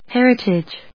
音節her・i・tage 発音記号・読み方
/hérəṭɪdʒ(米国英語), ˈherʌtʌdʒ(英国英語)/